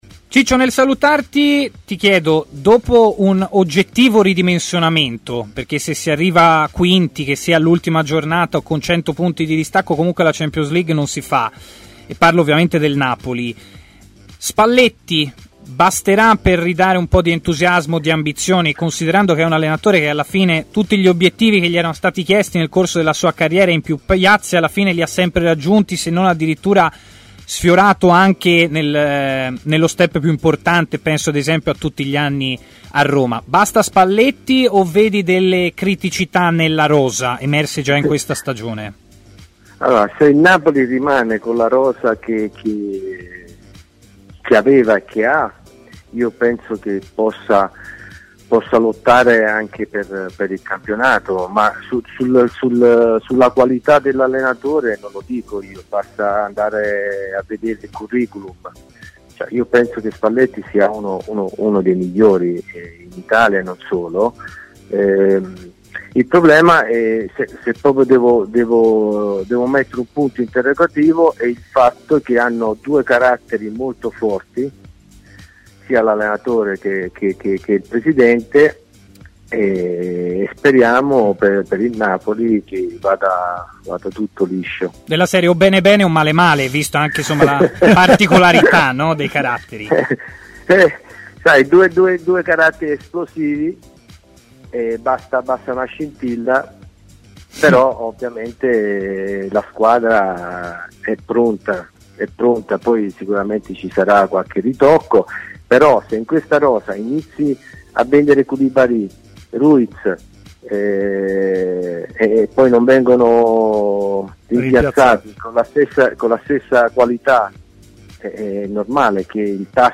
L'ex attaccante Francesco Baiano ha così parlato a TMW Radio, nel corso di Stadio Aperto, a partire dal cammino dell'Italia agli Europei: "Siamo tornati ad essere l'Italia, quella che gioca bene a calcio e diventa una squadra pretendente al titolo finale, anche se non da sola.